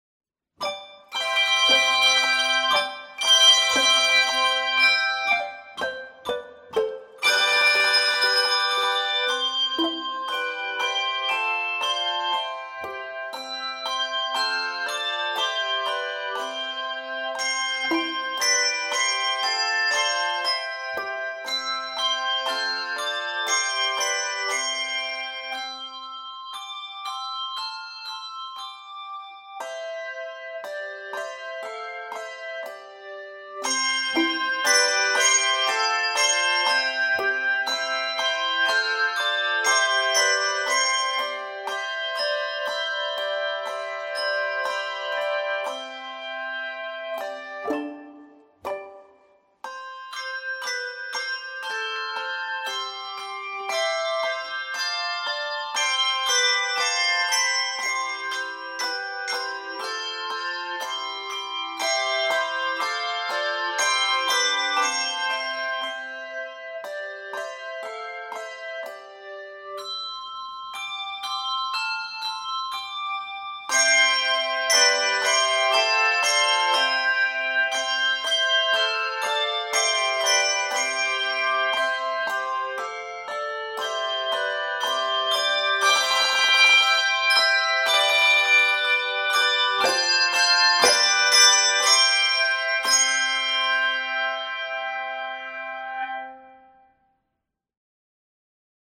energetic arrangement
Key of C Major.